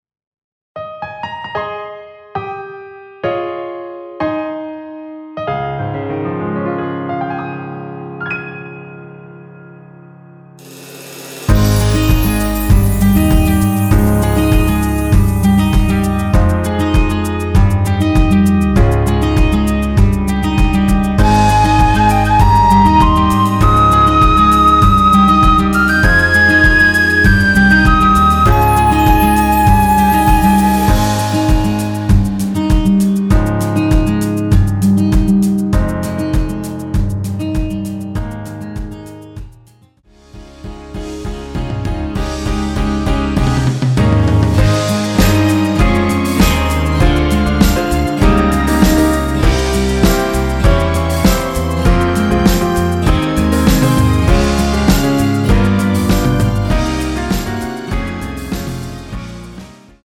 MR입니다.
앞부분30초, 뒷부분30초씩 편집해서 올려 드리고 있습니다.